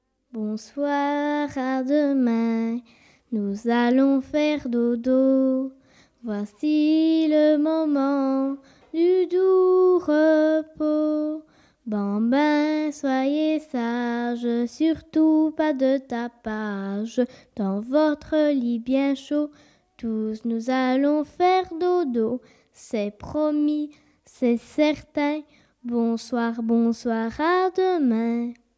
Genre : chant
Type : berceuse